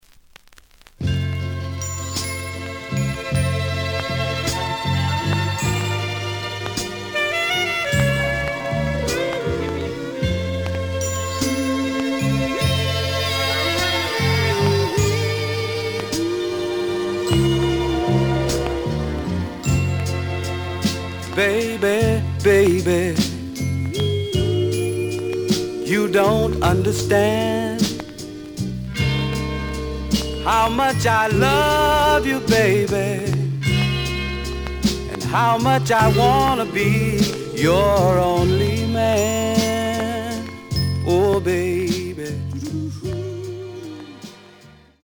The listen sample is recorded from the actual item.
Some click noise on A side due to scratches.)